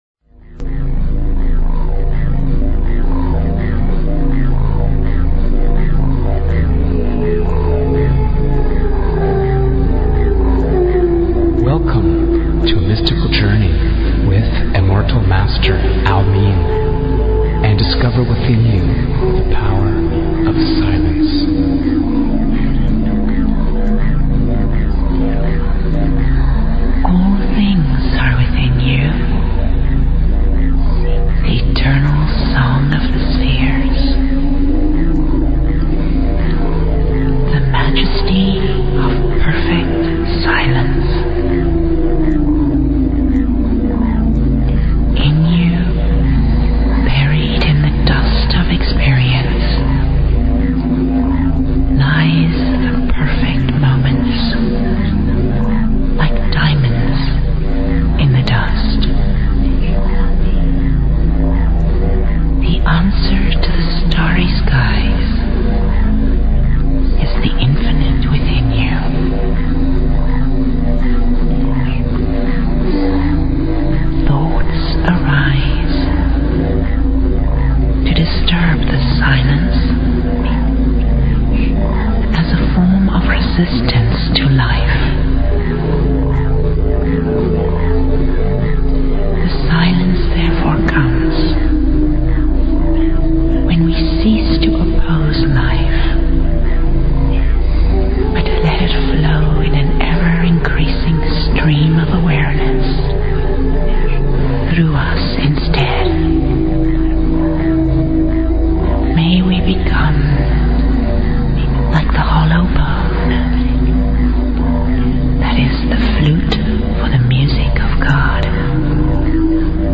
Talk Show Episode, Audio Podcast, Secrets_of_the_Hidden_Realms and Courtesy of BBS Radio on , show guests , about , categorized as
These interviews are powerful enough to change your life!